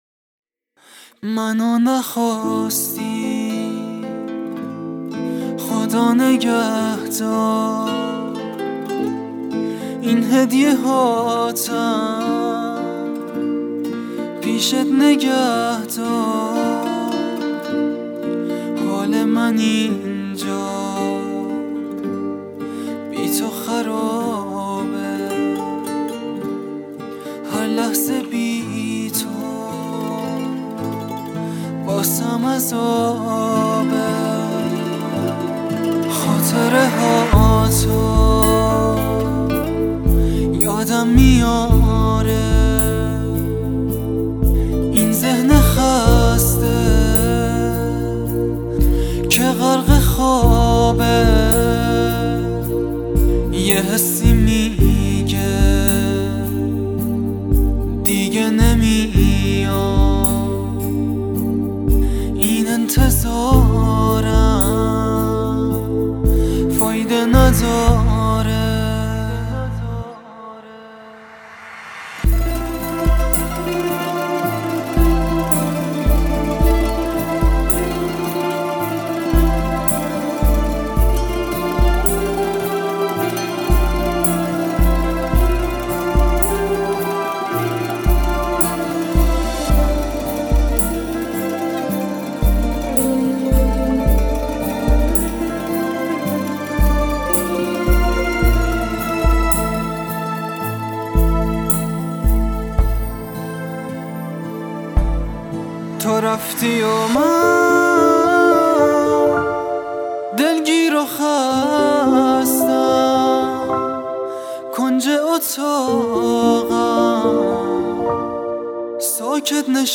دانلود آهنگ غمگین جدید